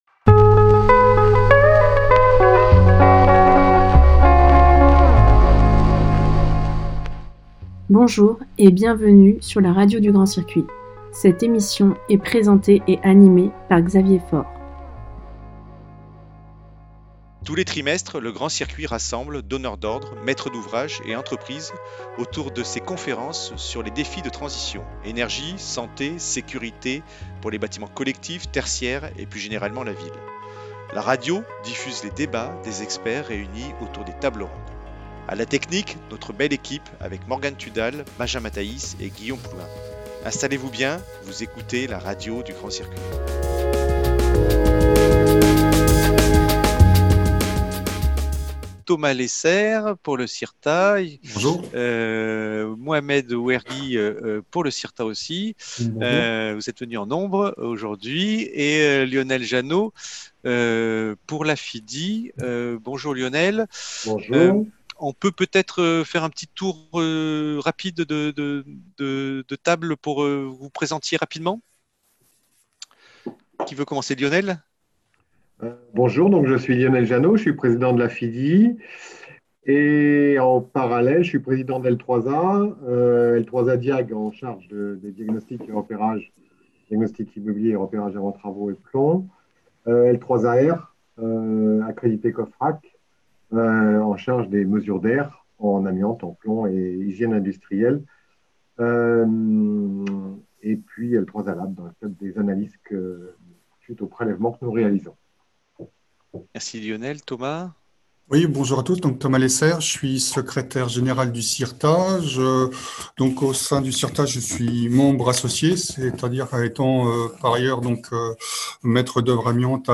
Table ronde – La mesure d’empoussièrement : une garantie pour tous les intervenants